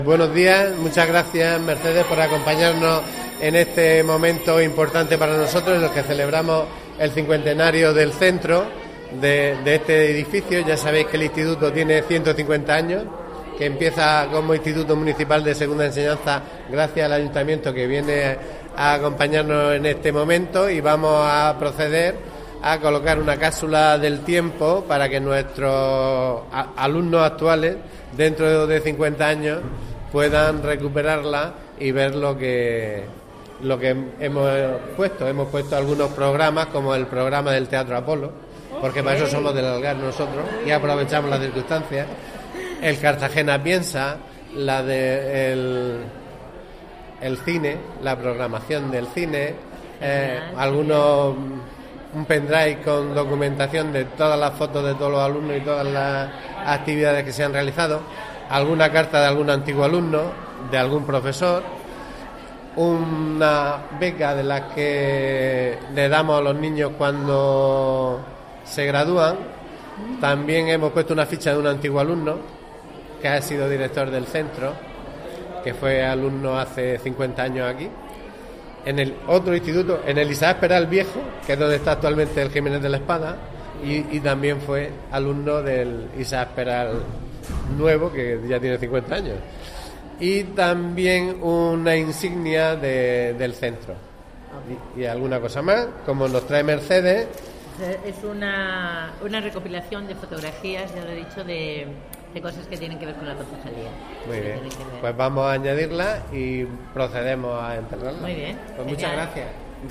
Audio: Declaraciones de la concejala de Educaci�n, Mercedes Garc�a, sobre la ceremonia de la C�psula del Tiempo (MP3 - 330,53 KB)